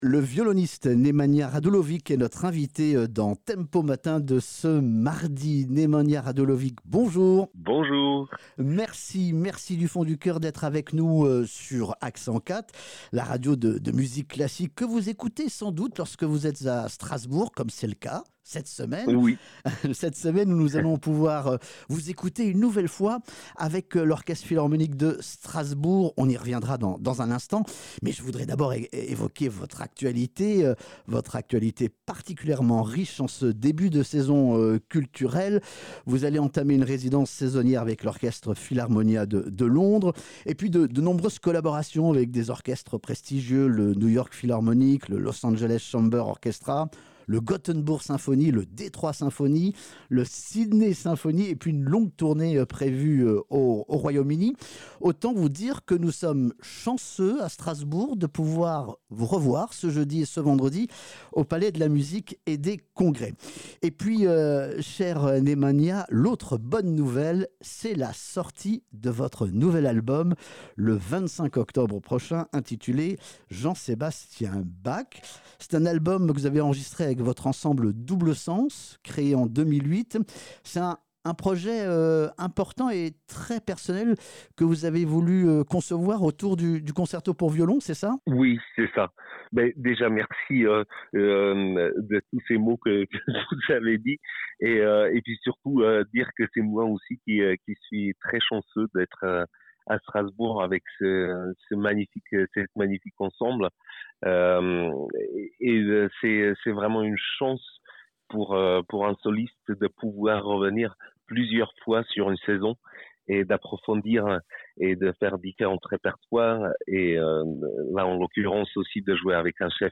Nemanja Radulovic s’est confié dans TEMPO MATIN ce mardi 8 octobre entre 8H00 et 9H00.
Emission-speciale-Nemanja-Radulovic.mp3